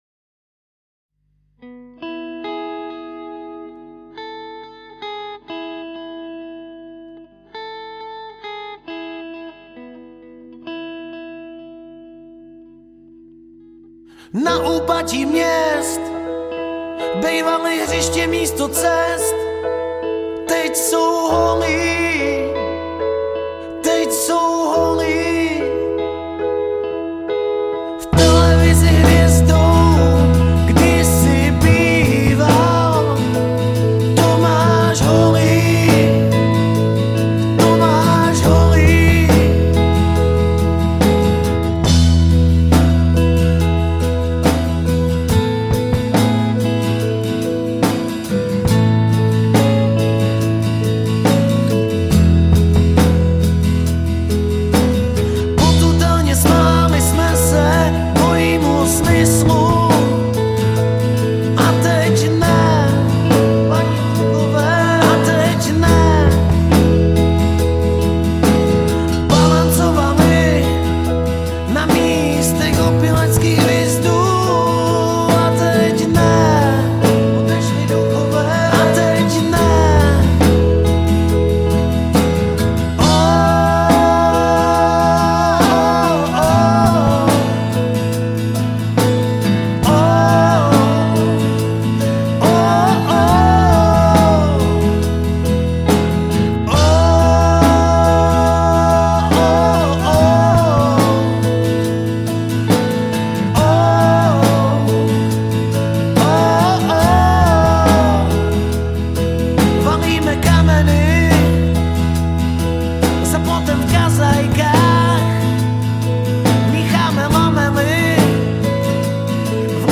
Žánr: Rock
pop/rockové kapely